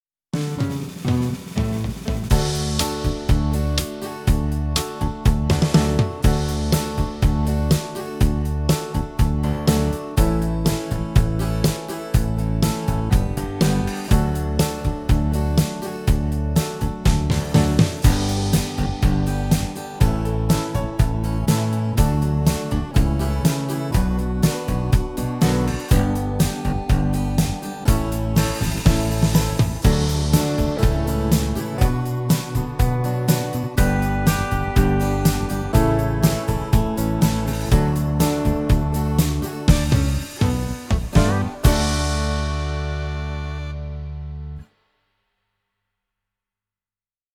Intro/endings works in Major.
Akkordforløb i introer og endings fungerer i dur akkorder.
DEMO